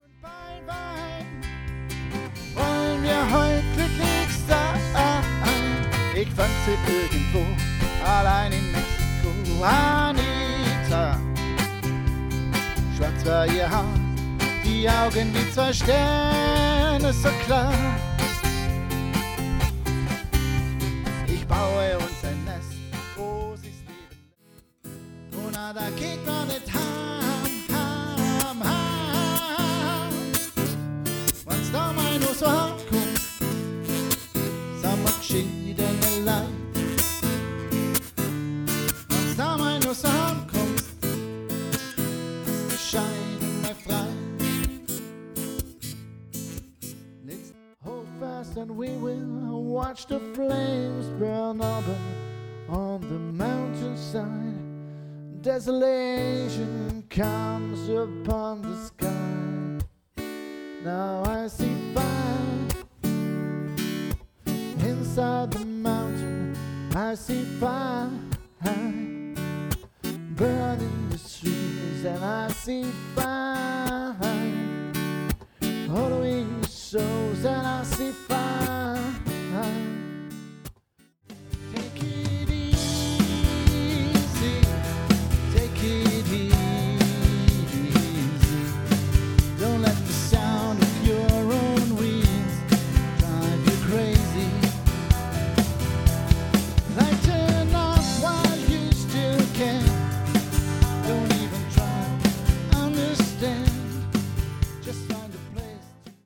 Unplugged - Pure Music